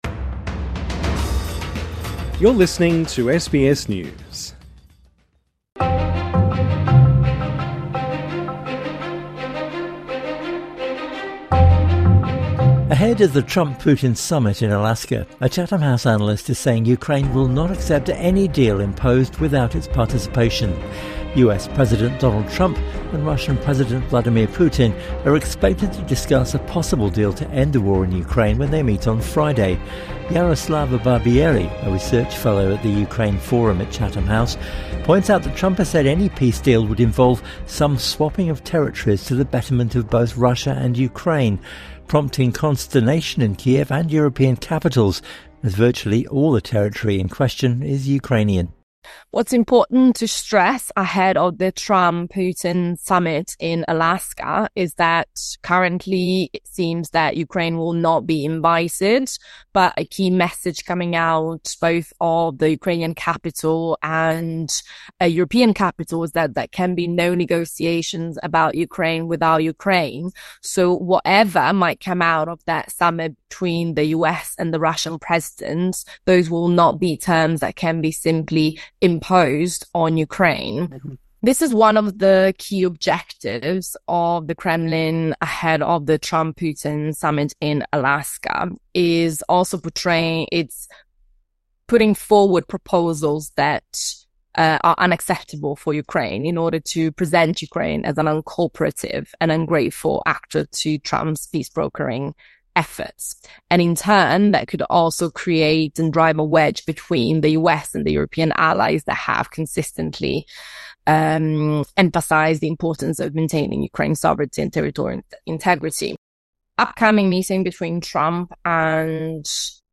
Weekend One on One